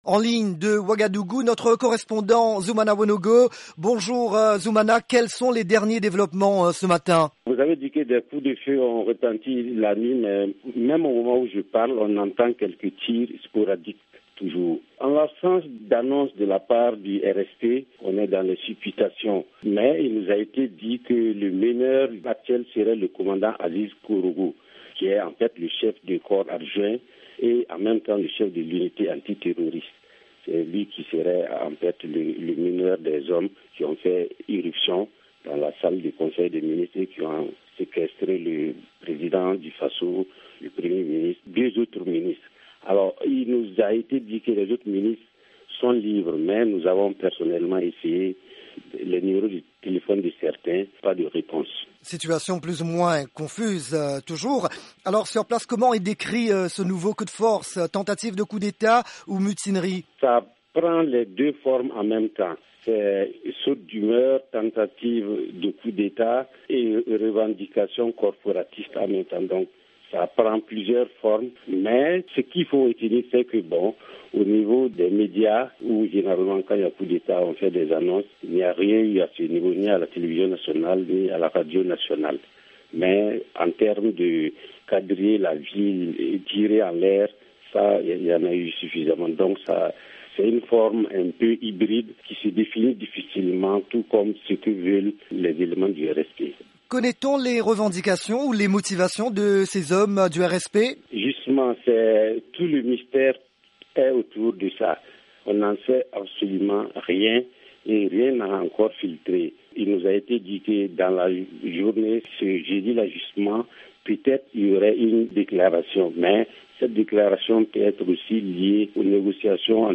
joint tôt ce matin à Ouagadougou